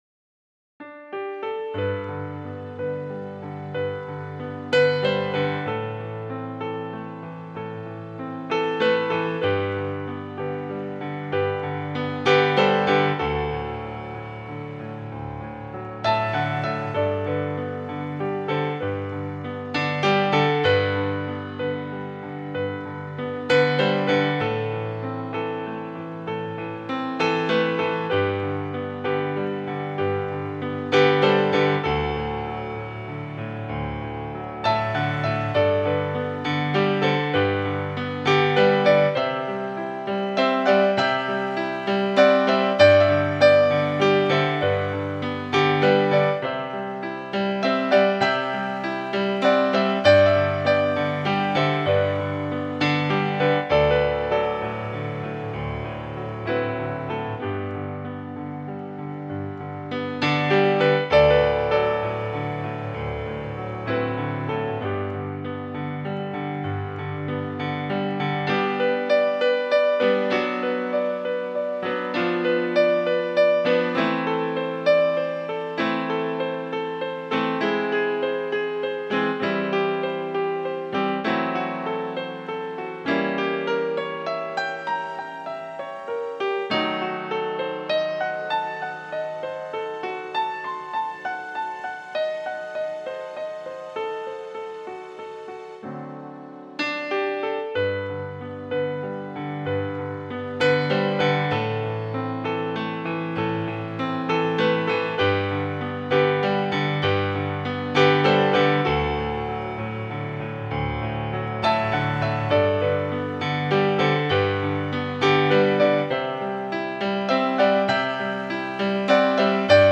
Original Piano / Keyboard